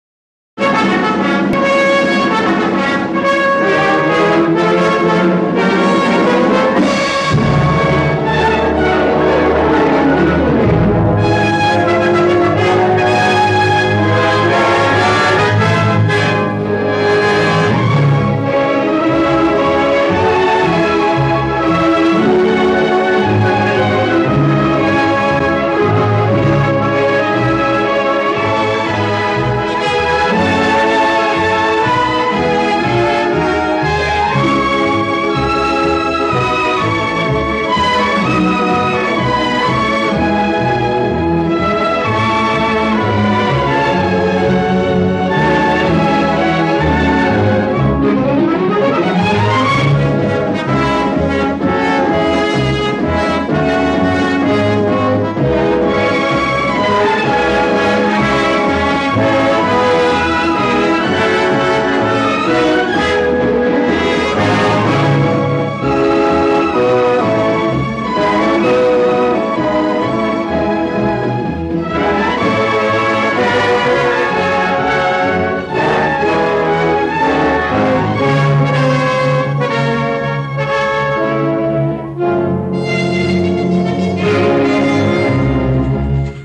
电影原声演奏版